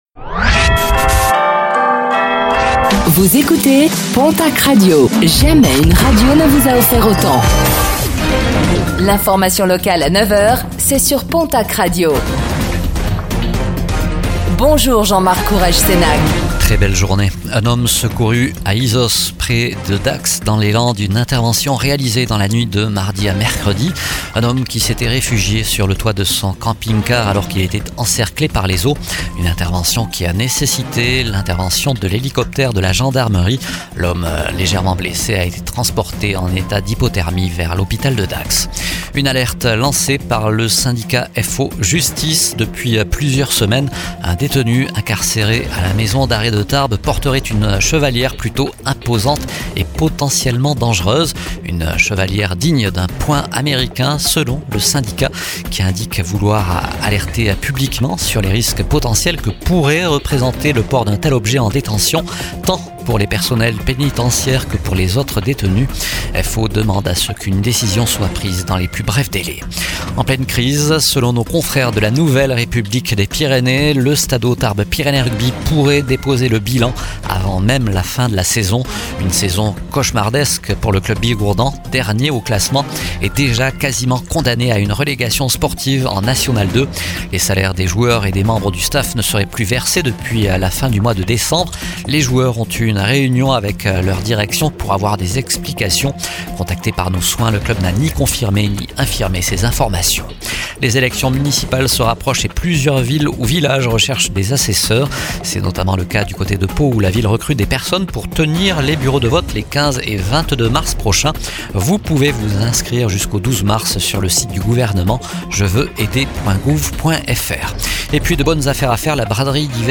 Réécoutez le flash d'information locale de ce jeudi 19 février 2026